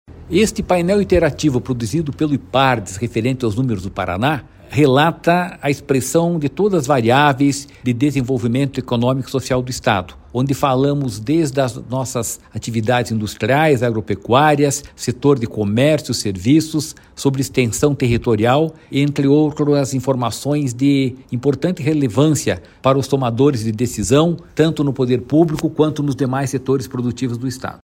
Sonora do presidente do Ipardes, Jorge Callado, sobre o painel "Paraná em Números"